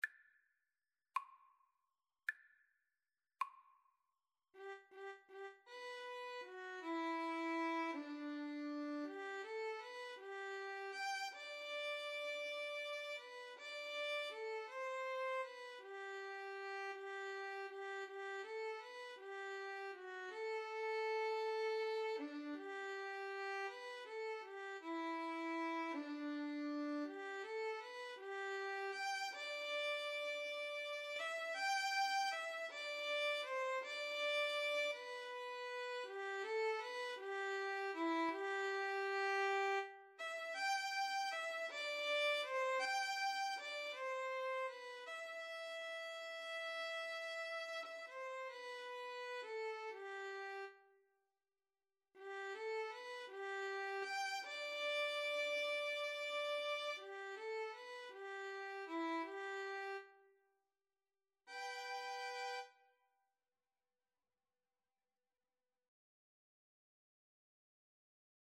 G major (Sounding Pitch) (View more G major Music for Violin Duet )
6/8 (View more 6/8 Music)
Andante ingueno
Violin Duet  (View more Intermediate Violin Duet Music)
Classical (View more Classical Violin Duet Music)